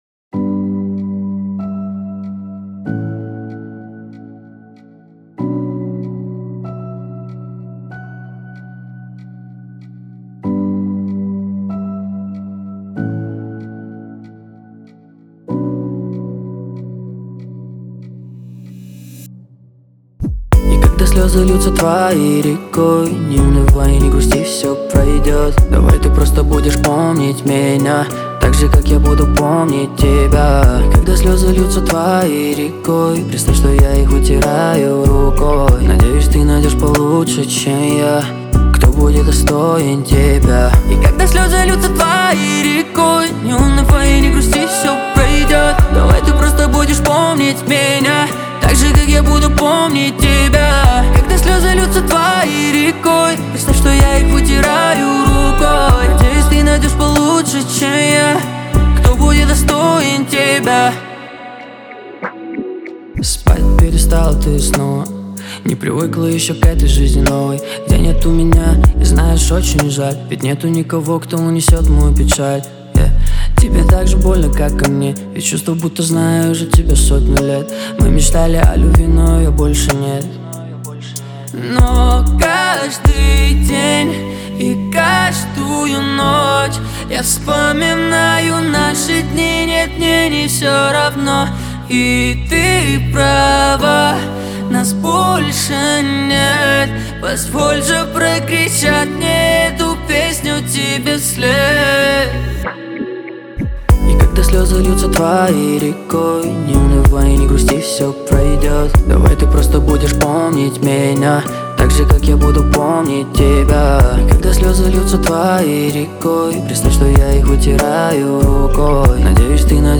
это эмоциональный трек в жанре альтернативного рока